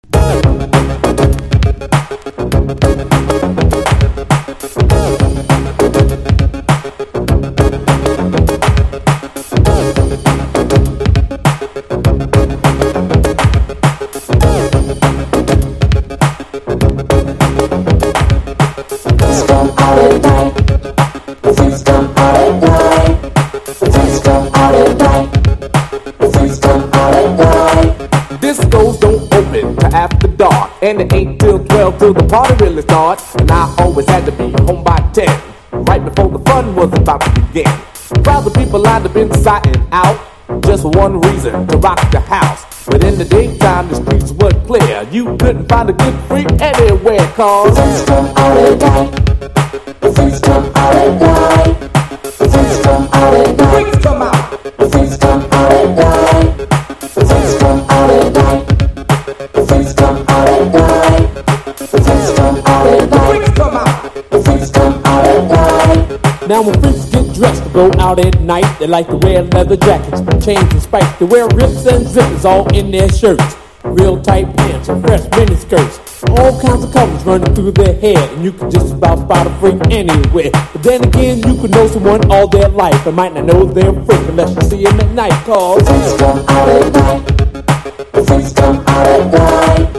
エレクトロサウンド炸裂のオケにキレの良いライミングが最高にかっこいいニュージャックスウィング！